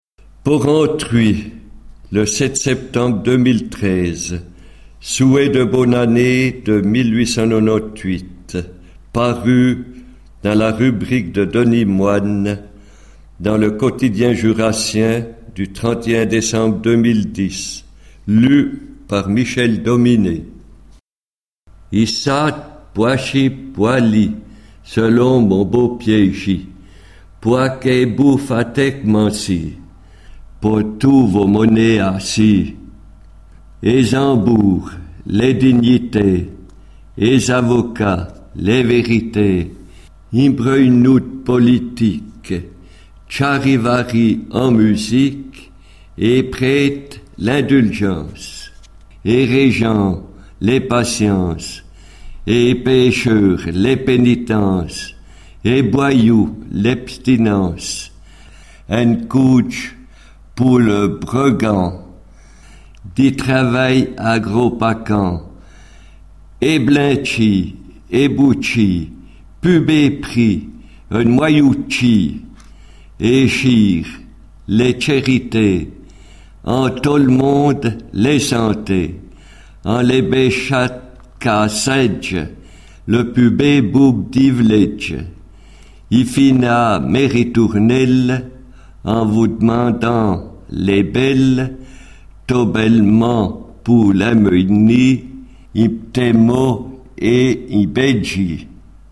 Un rimeur facétieux déroule ses couplets :